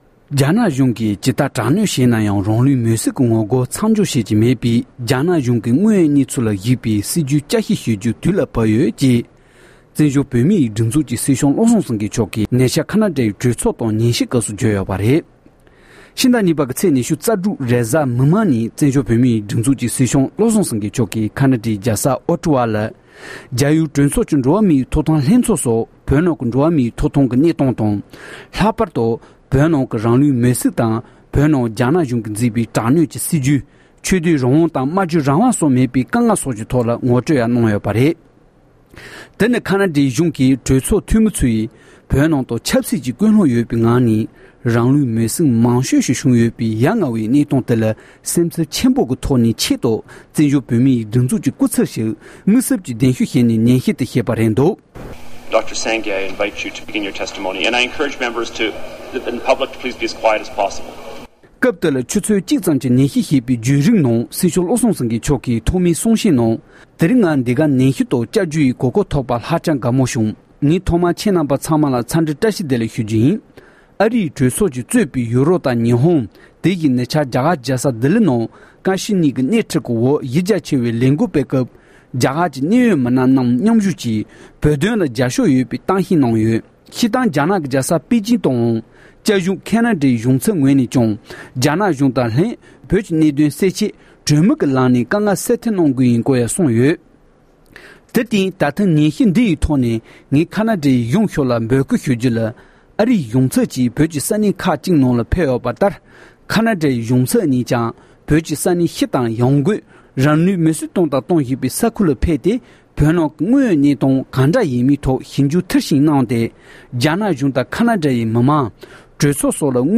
ཁེ་ན་ཌའི་གྲོས་ཚོགས་ནང་སྙན་སེང༌།